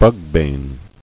[bug·bane]